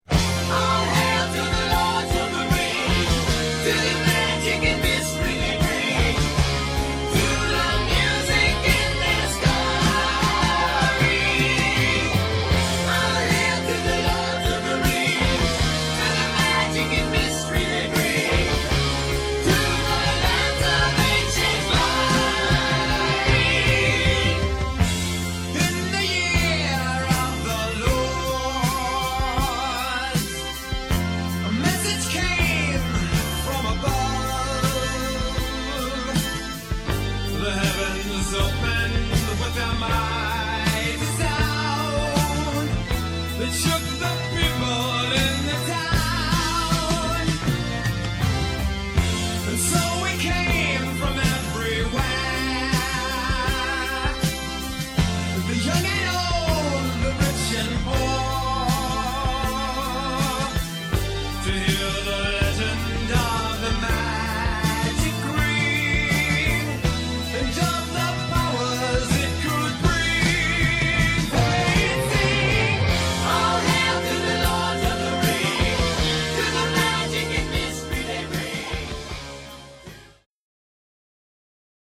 (rock)